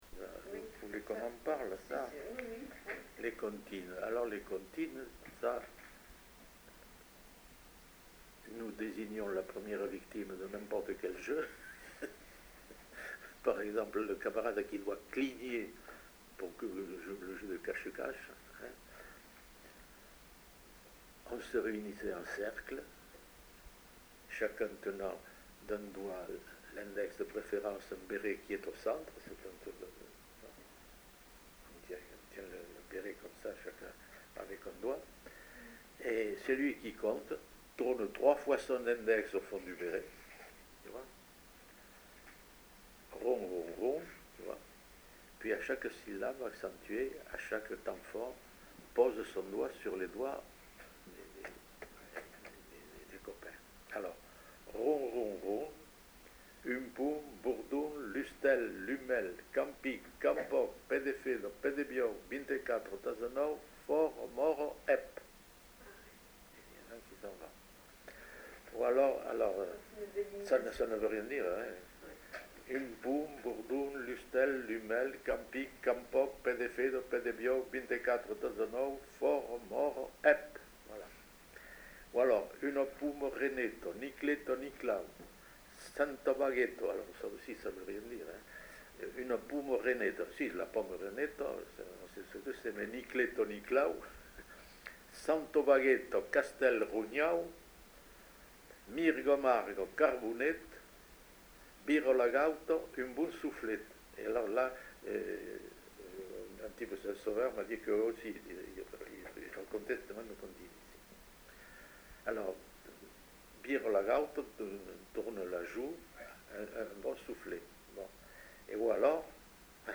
Lieu : Saint-Sauveur
Genre : forme brève
Effectif : 1
Type de voix : voix d'homme
Production du son : récité
Classification : comptine